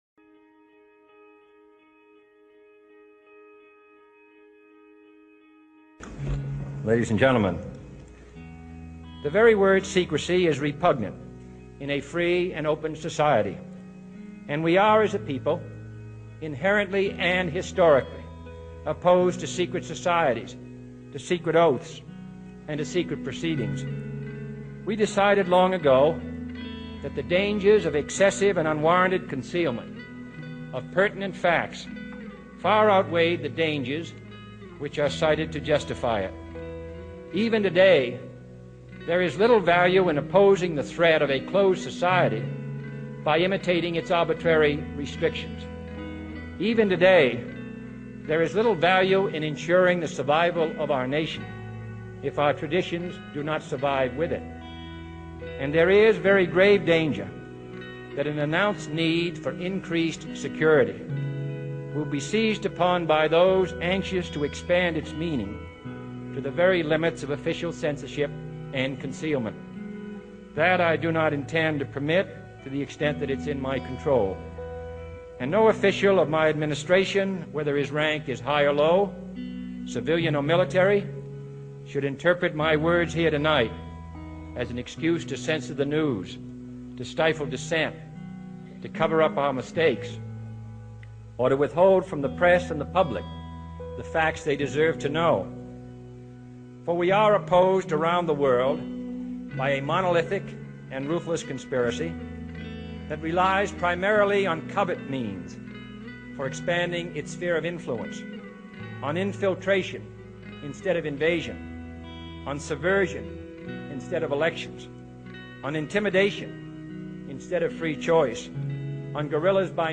John F. Kennedy - speech to the press and media
President John F. Kennedy Waldorf-Astoria Hotel, New York City April 27, 1961